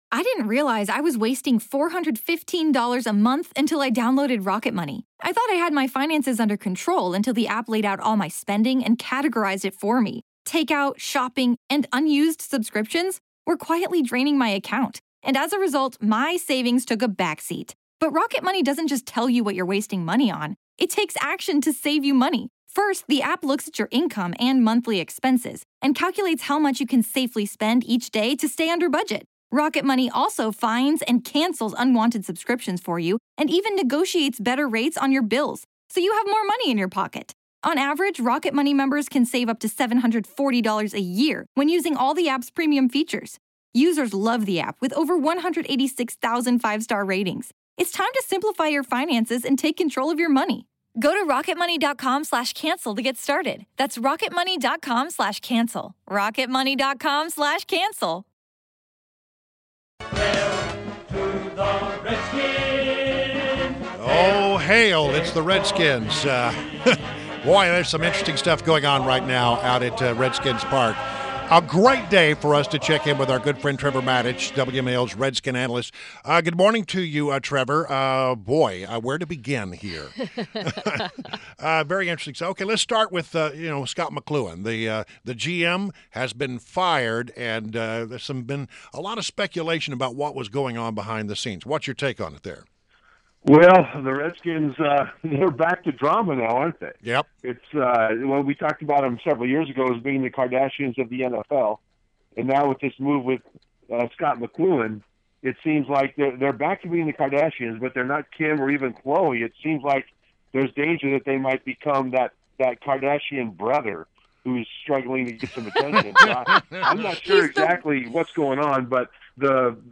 INTERVIEW – TREVOR MATICH – Former Redskins elite long snapper, WMAL’s Redskins analyst and Comcast SportsNet co-host